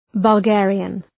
Προφορά
{bəl’geərıən}